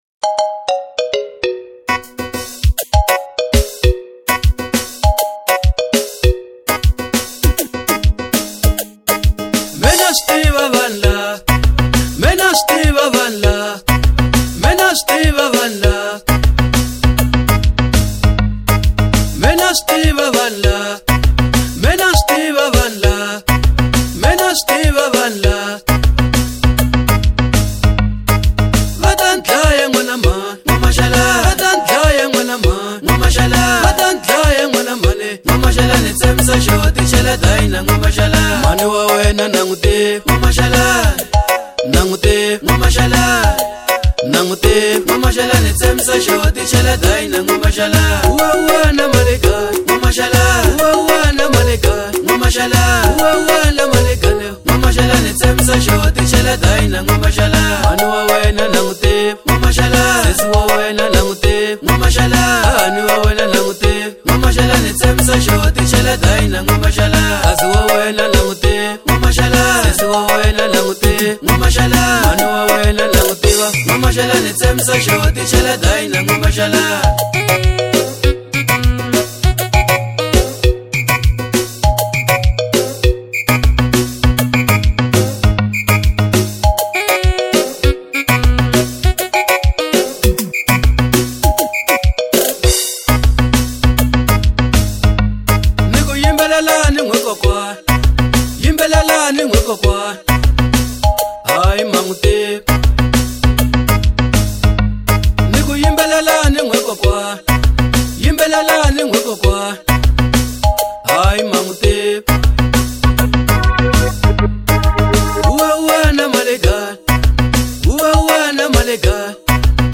04:29 Genre : Xitsonga Size